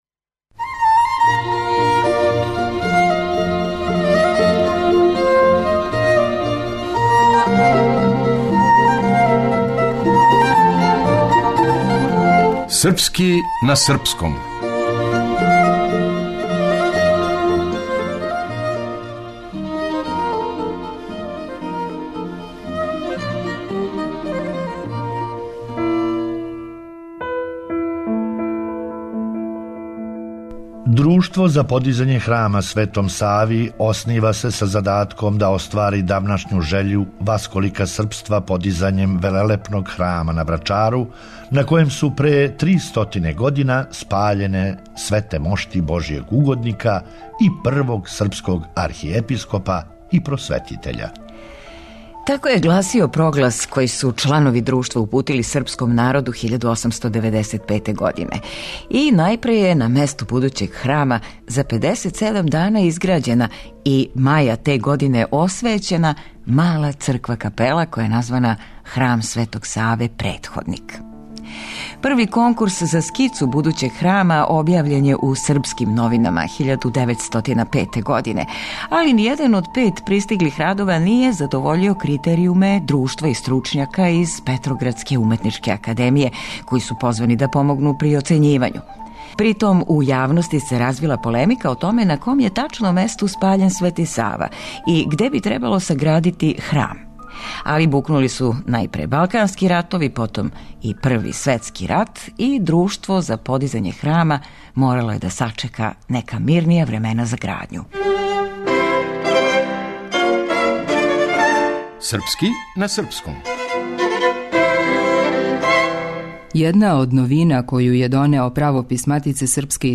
Драмски уметници